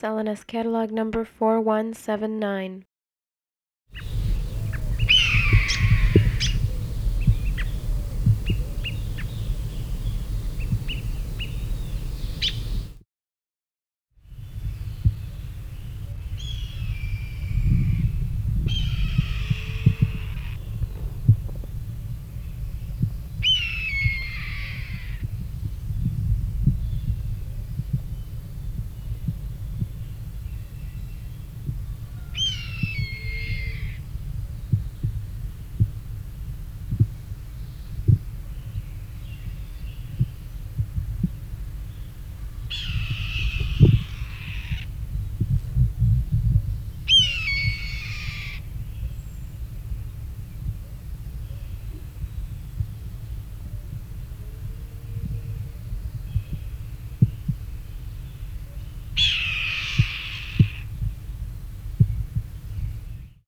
Red-tailed hawks are one of the most common raptors in North America, and their distinctive call is widely recognized even by people who don’t own a pair of binoculars or walk around looking up at tree branches as a hobby.
The National bird of the U.S., the bald eagle, has frequently been subject to having its less majestic call replaced with the soaring sound of the red-tailed hawk.
Red-tailed-hawk-call.wav